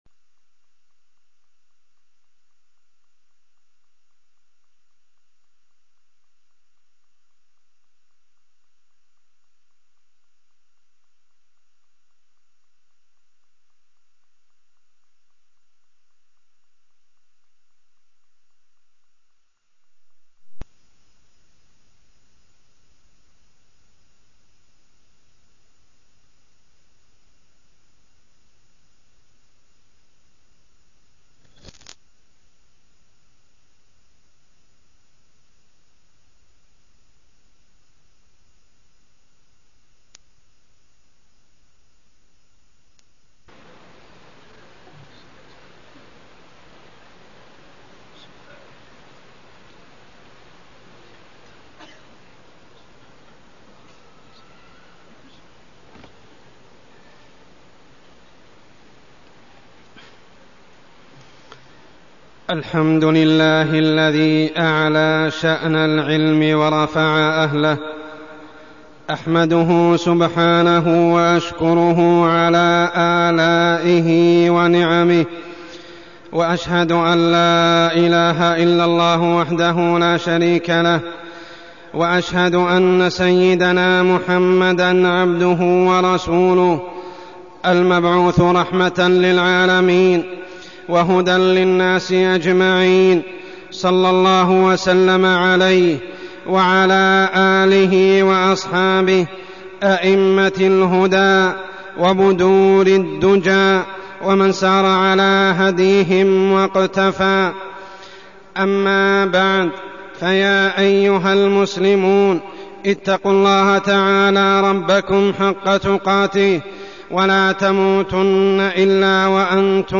تاريخ النشر ١ جمادى الأولى ١٤١٧ هـ المكان: المسجد الحرام الشيخ: عمر السبيل عمر السبيل العناية بأسس المناهج